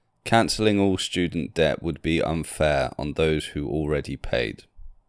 Dictation 2